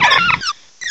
cry_not_budew.aif